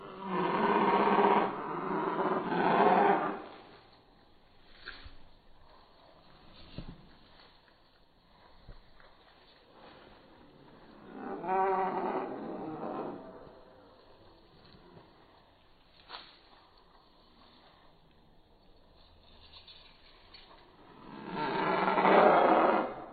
Download Dinasour sound effect for free.
Dinasour